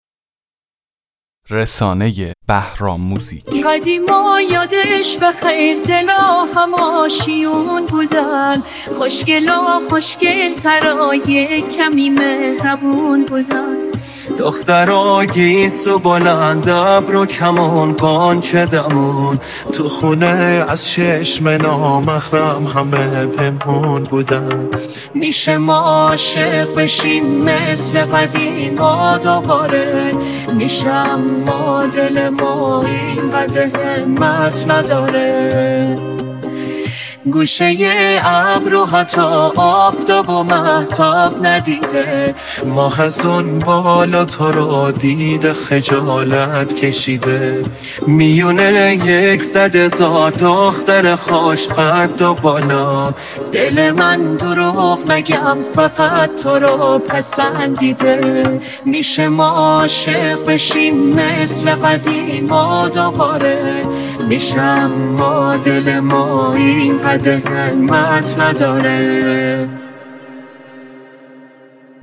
این آهنگ با هوش مصنوعی ساخته شده است